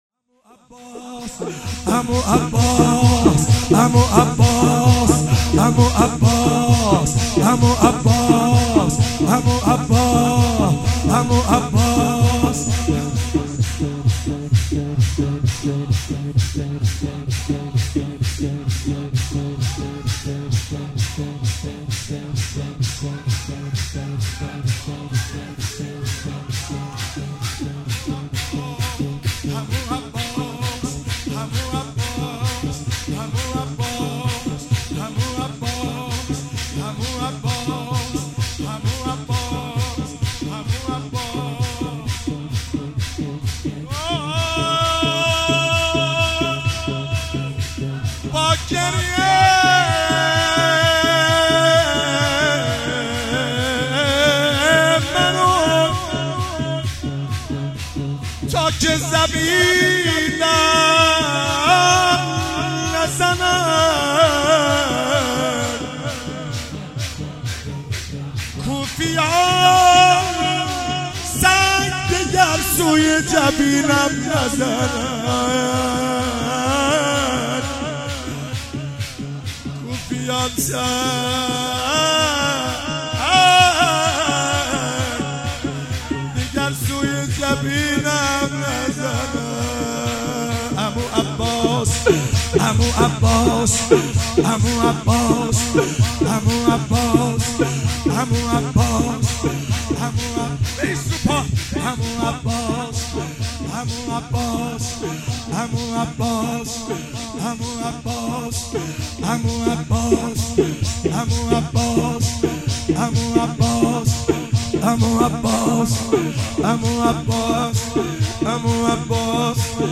شب تاسوعا محرم 96 - شور - عمو عباس